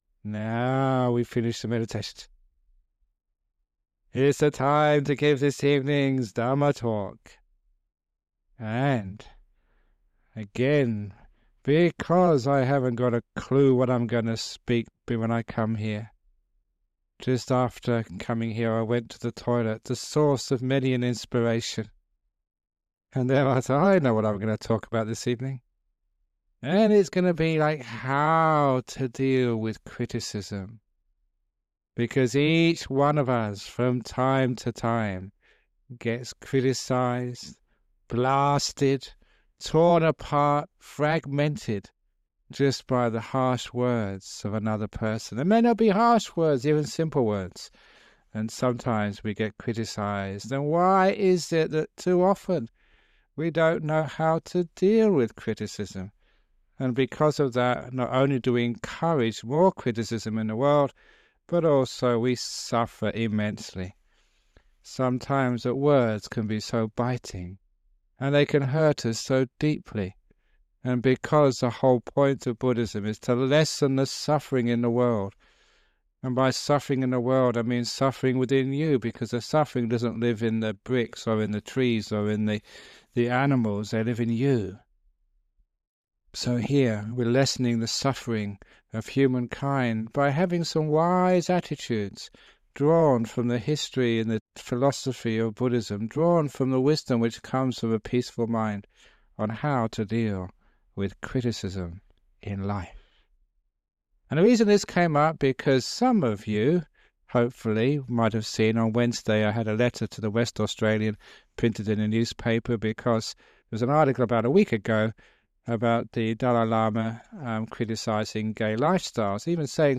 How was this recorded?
It has now been remastered and published by the Everyday Dhamma Network, and will be of interest to his many fans.